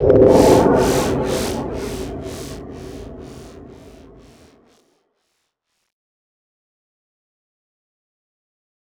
Processed Hits 21.wav